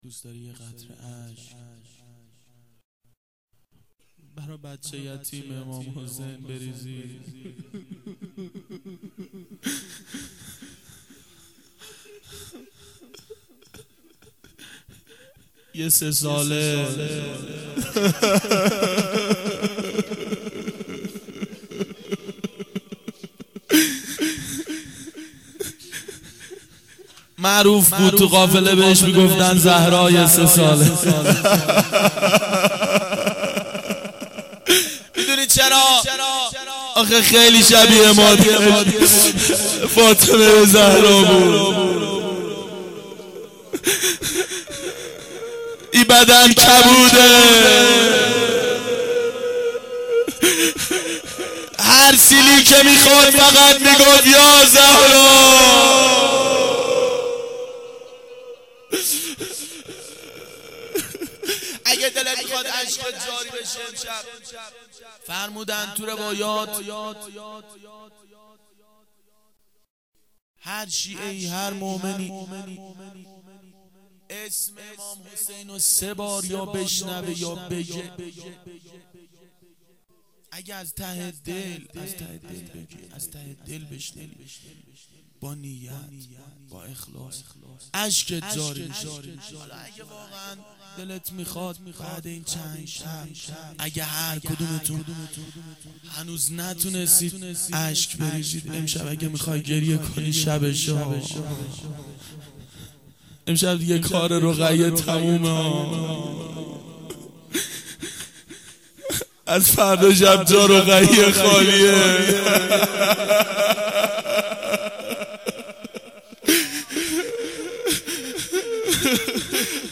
روضه حضرت رقیه سلام الله علیها
04-roze-hazrate-roghaye.mp3